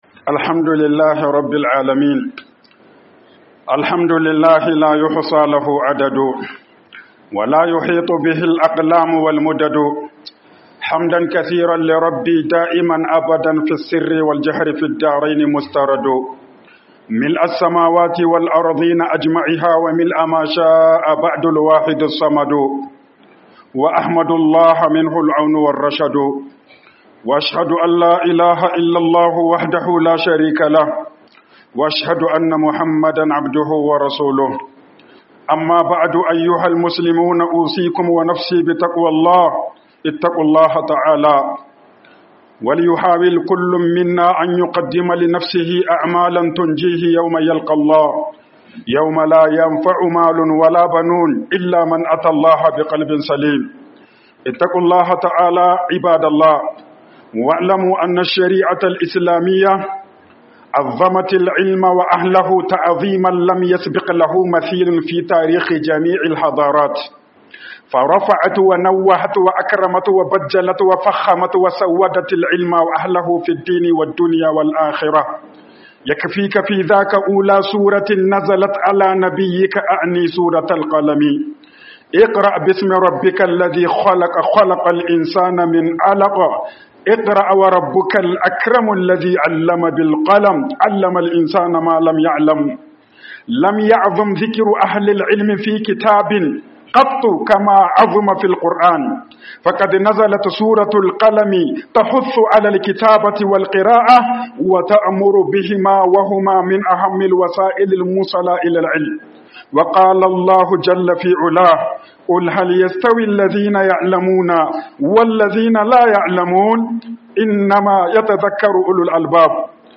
RENTRÉE 01 - HUƊUBOBIN JUMA'A